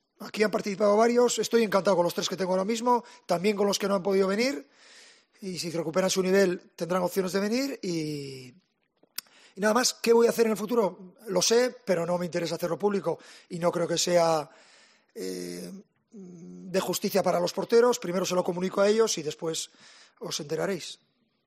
"Eso ya forma parte de la historia, fue el año pasado. Hemos sacado nuestras conclusiones y analizado qué cosas hicimos bien y cuáles mal, pero no aporta a lo que buscamos en presente. Mi opinión sigue siendo la misma, todavía hay muchas cosas que mejorar y el que piense que por ganar 6-0 a Alemania le va a ganar por más a Grecia conoce poco la actualidad del fútbol, así que tenemos que intentar no caer en ese error, intentar ser mejor en el campo y superar al rival en el aspecto defensivo y ofensivo, eso no cambia ante ningún rival", expresó Luis Enrique en rueda de prensa.